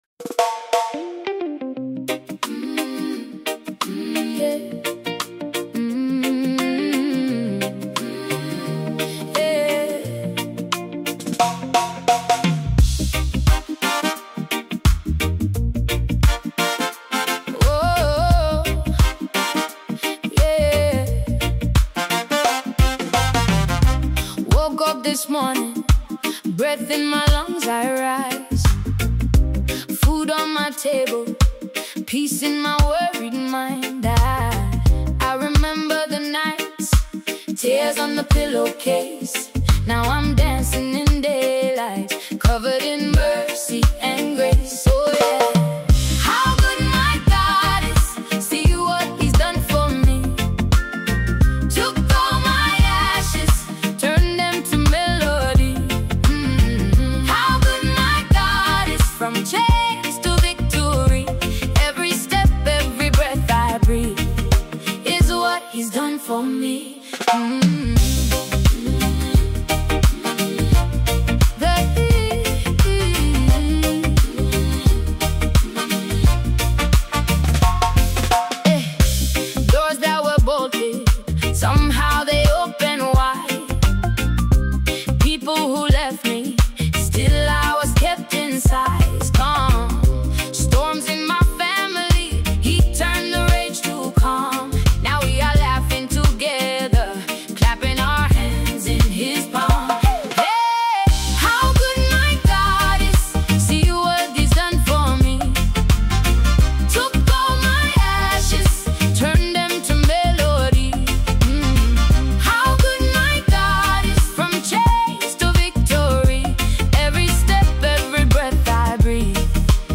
It carries a quiet joy that builds gradually.